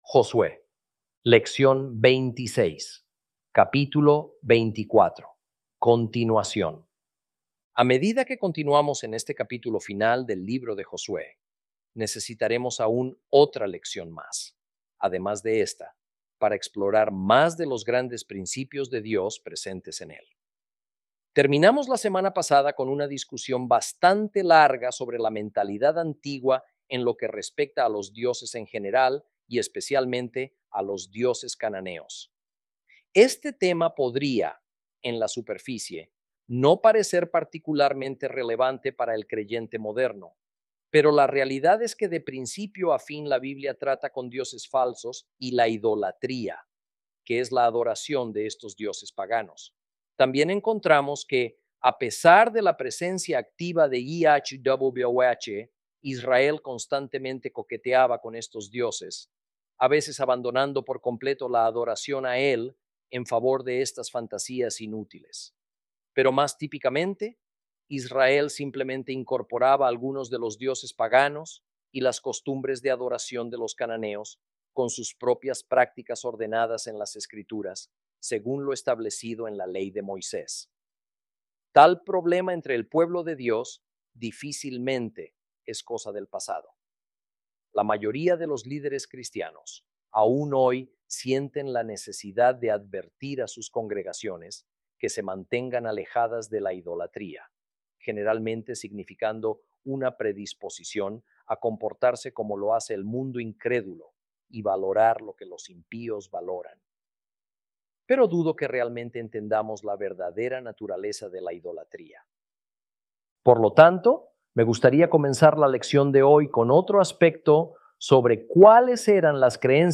Lección 26 – Capítulo 24 Continuación